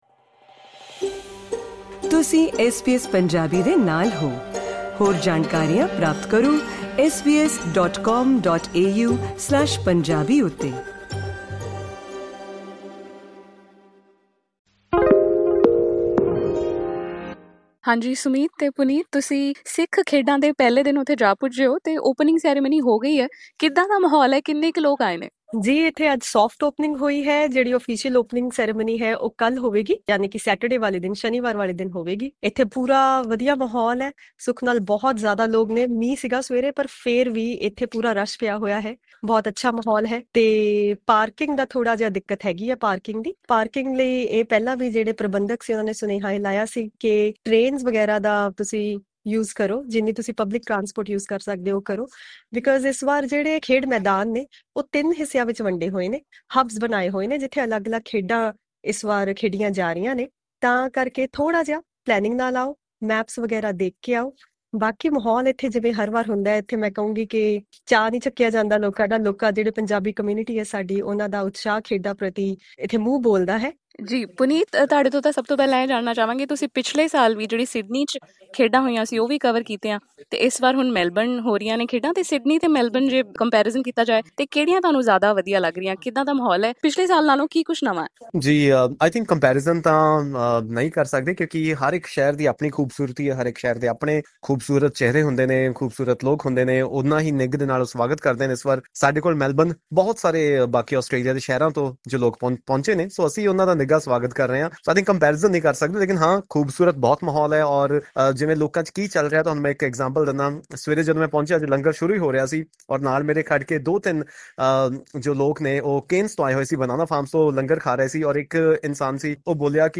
The SBS Punjabi team is live on the ground, capturing all the action at the Australian Sikh Games 2026 Credit: SBS Punjabi